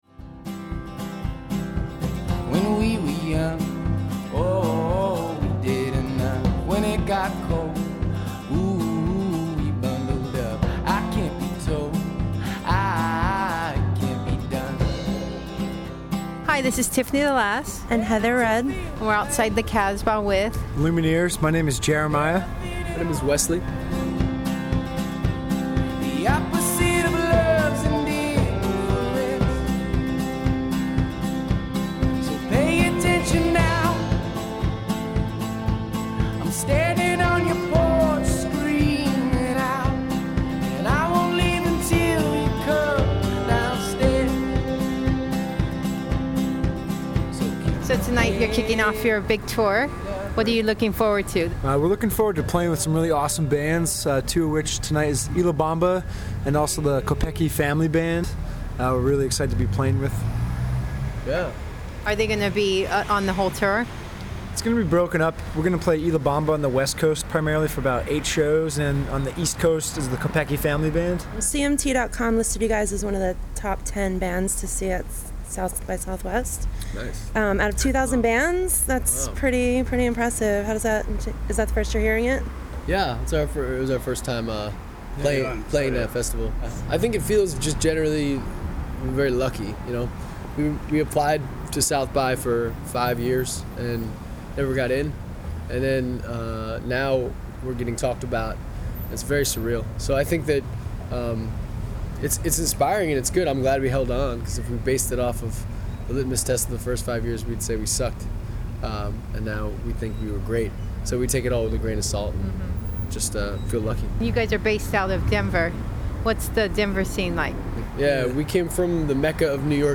recorded at the casbah in san diego. button up your plaid shirt, jump on your horse, put in your earbuds, and start your gallop to the lumineer soundtrack. you'll be sure to want to join in the bona fide esprit de corps.